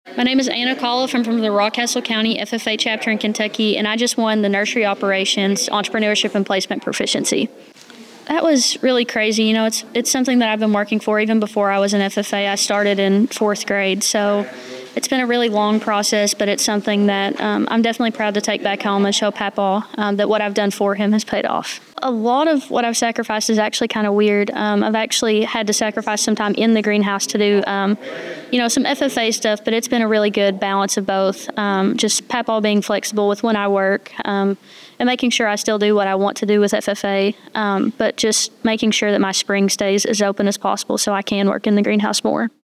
By Published On: October 25th, 20240.4 min readCategories: Convention Audio